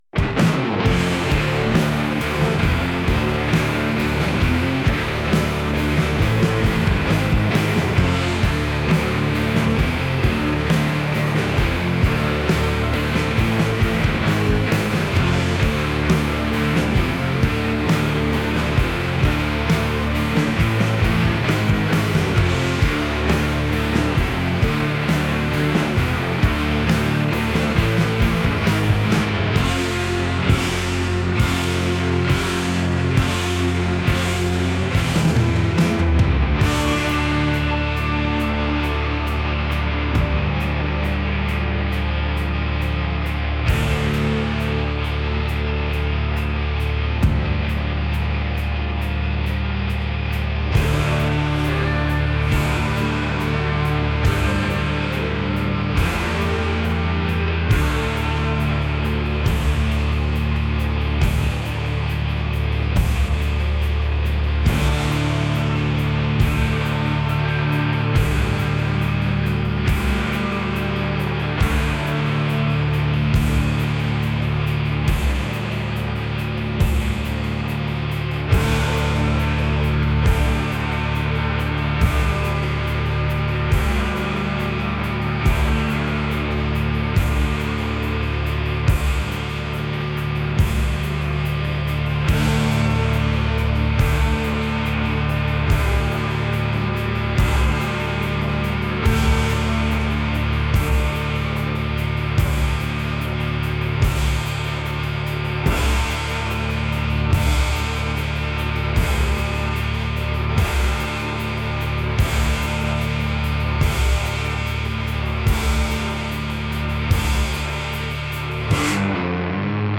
alternative | rock